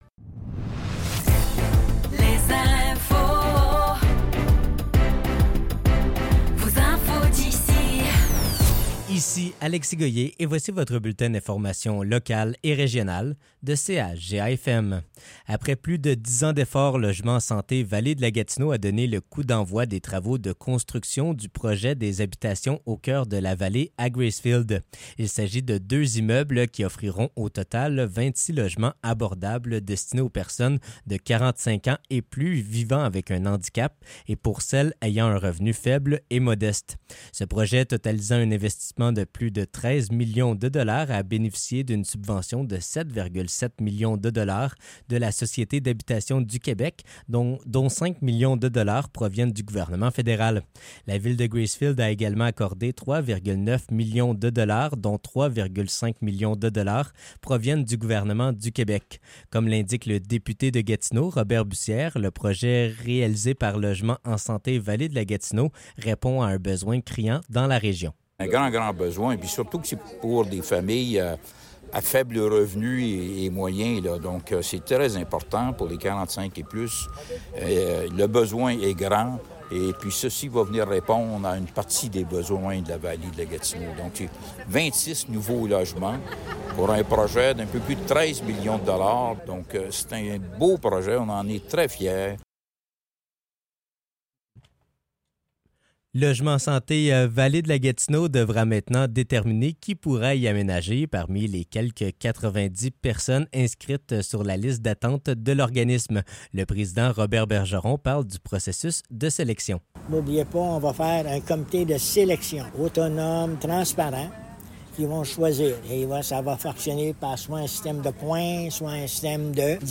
Nouvelles locales - 27 Août 2024 - 10 h